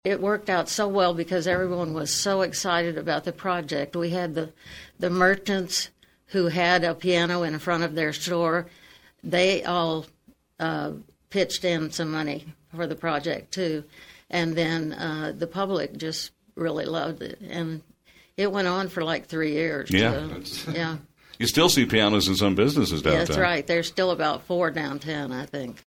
On KVOE’s On-Air Chat on Wednesday, organizers discussed how the group’s mission has changed over time.